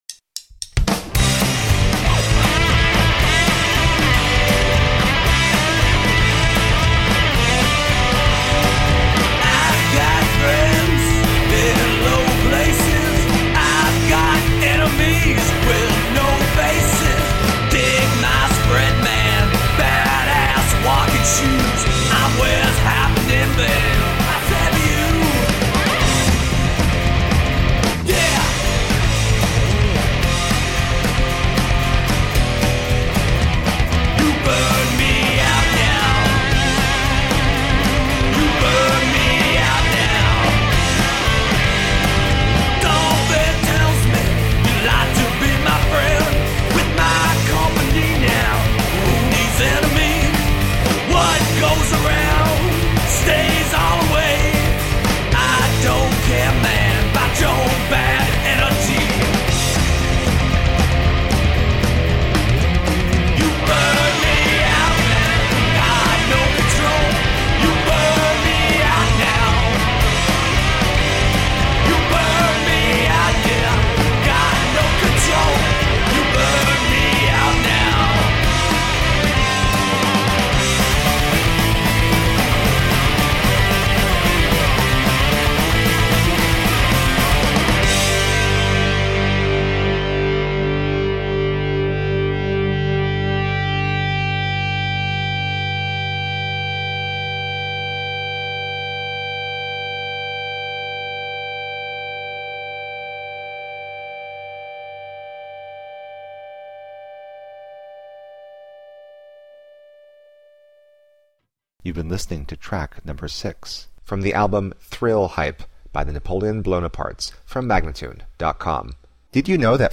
Obnoxious high-energy rock.
Biting guitars and gritty, pissed off rock vocals anyone?